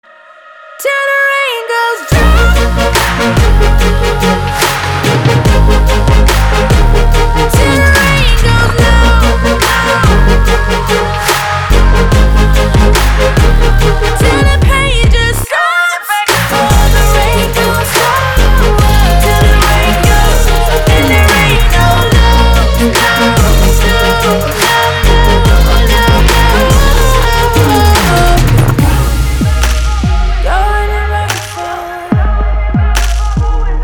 • Качество: 320, Stereo
поп
женский вокал
dance
Electronic
Downtempo
красивый женский голос